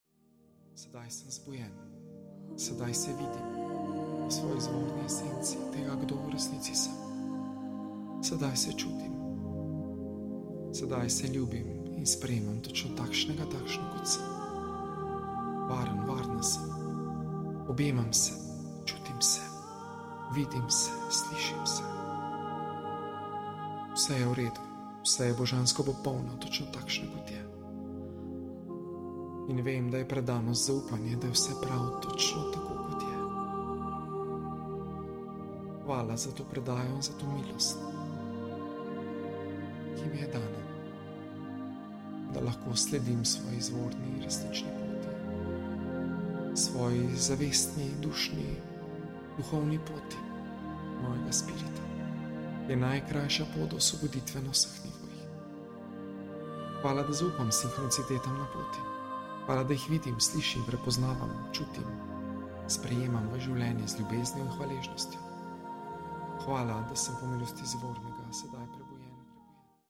Posnetek meditacije je bil izrezan ter zvočno obdelan iz 22. srečanje članstva Osvobajanje od iluzij, 30.05.2024 (Meditacija Zdravljenje toksičnih odnosov).